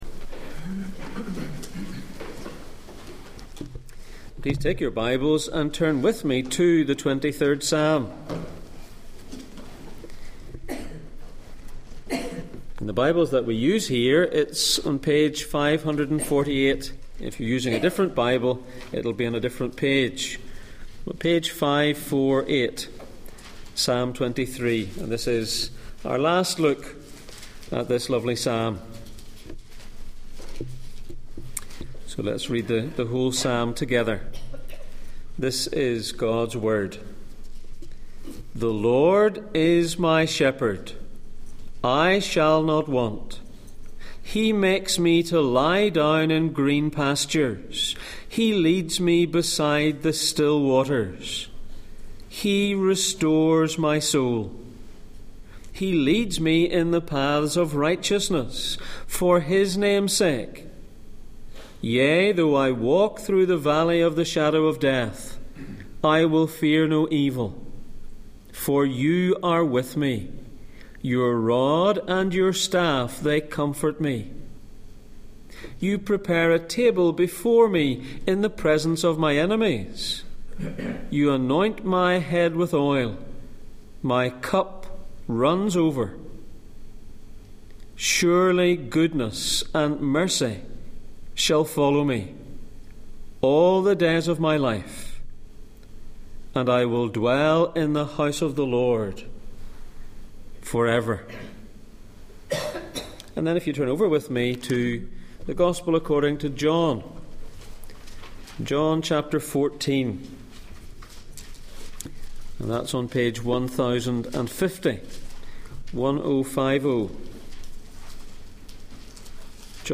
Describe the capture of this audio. The Good Shepherd and His Sheep Passage: Psalm 23:6, John 14:1-7, Genesis 50:19-20, Romans 8:28-29 Service Type: Sunday Morning